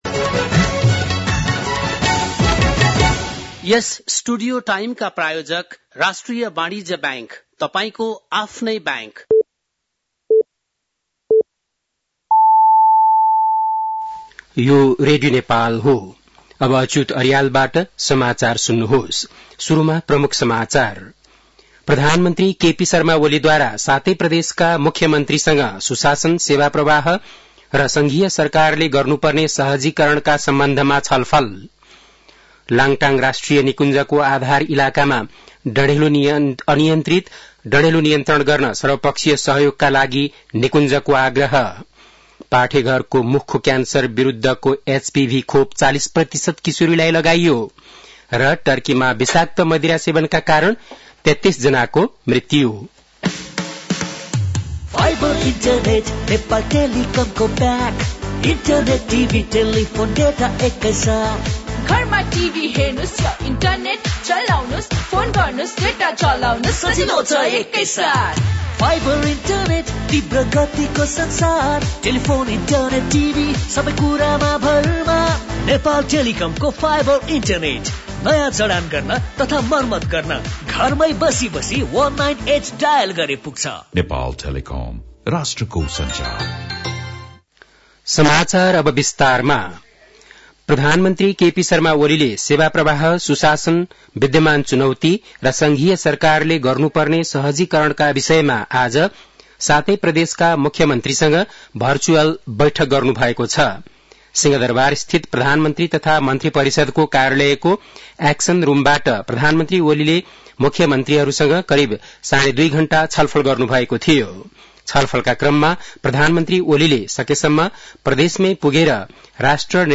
बेलुकी ७ बजेको नेपाली समाचार : २६ माघ , २०८१
7-PM-Nepali-News-10-25.mp3